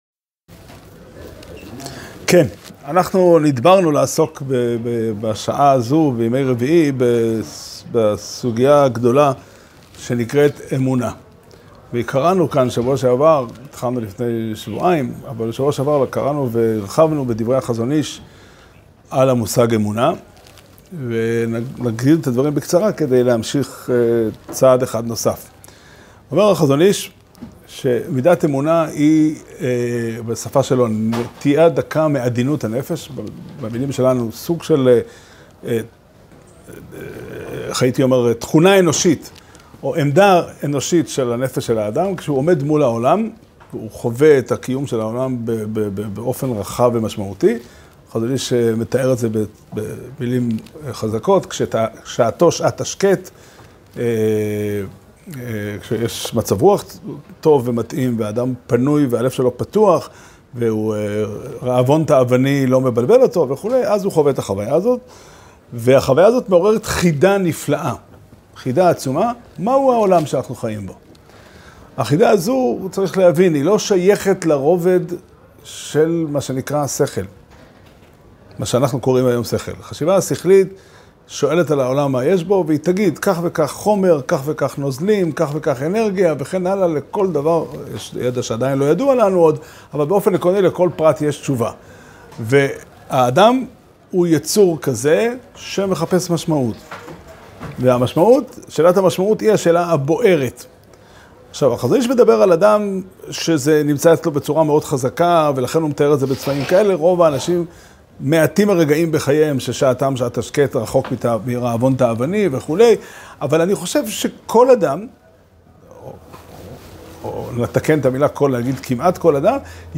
שיעור שנמסר בבית המדרש פתחי עולם בתאריך כ"ו תמוז תשפ"ד